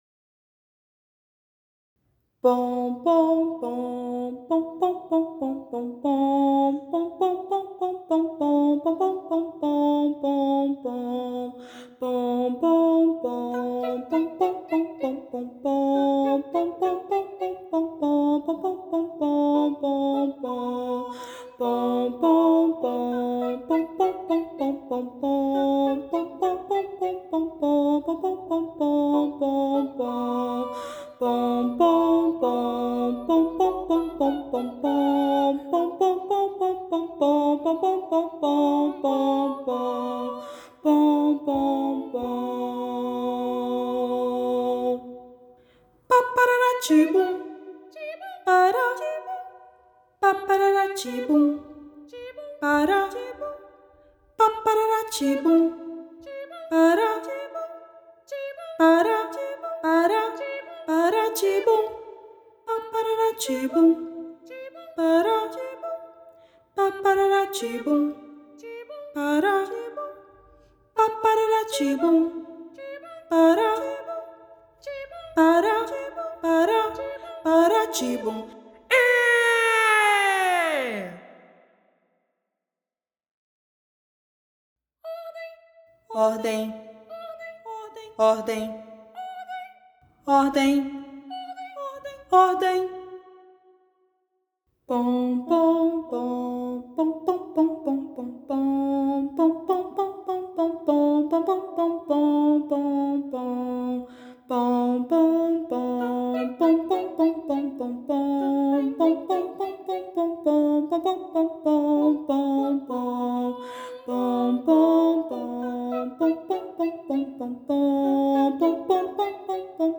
“3 Canções para os pequeninos” é um conjunto de três pequenas peças para coro infantil a três vozes.
Na segunda canção (Acalanto), Rafael Bezerra traz uma singela canção de ninar, com texto próprio, que pode ser vista como uma oração de proteção, enquanto na terceira canção (Marcha), podemos sentir o vigor das bandas militares não apenas pela rítmica utilizada como também pelo uso de onomatopeias que nos transportam para esse universo.
Voz Guia 3